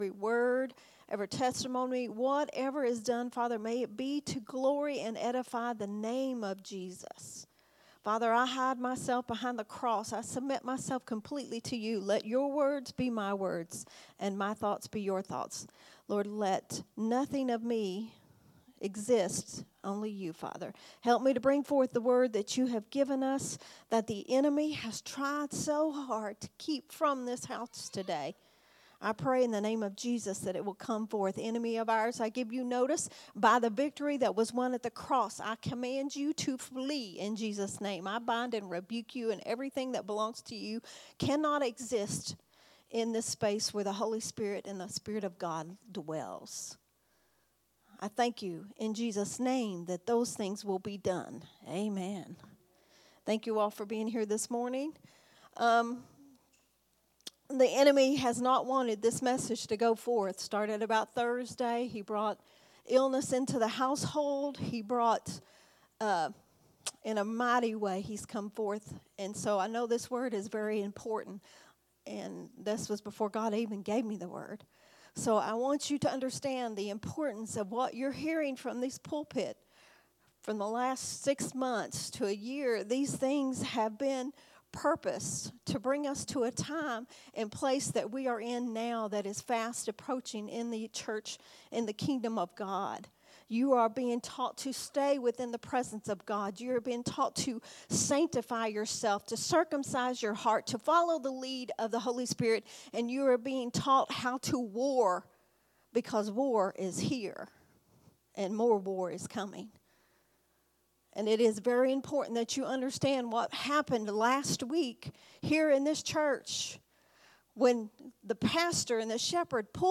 recorded at Unity Worship Center on March 3rd, 2024.